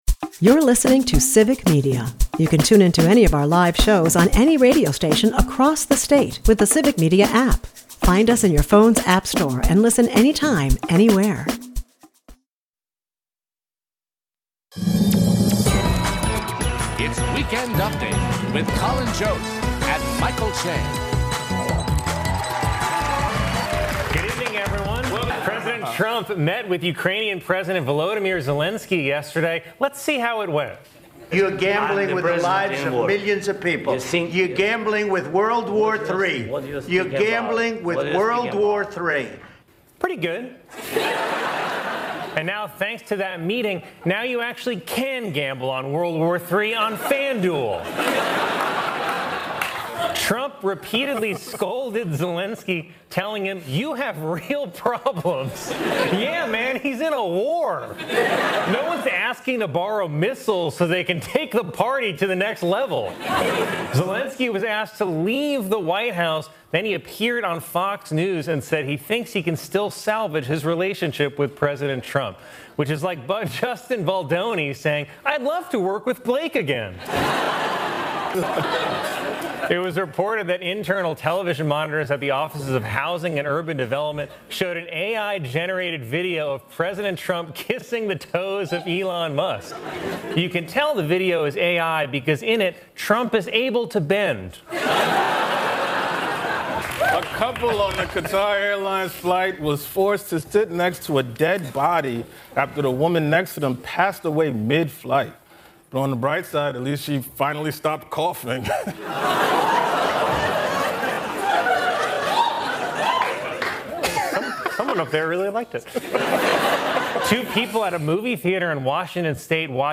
Today we're talking some breaking Idiocratic news: Russia is working to rebuild a network of spies, under the cover of diplomats (you can't make this stuff up!). We listen to a rant from Texas representative Jasmine Crockett calling on Trump to tell the truth about Russia invading Ukraine, and also to Bill Burr comparing Musk and Hitler.